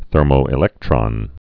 (thûrmō-ĭ-lĕktrŏn)